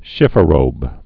(shĭfə-rōb, shĭfrōb)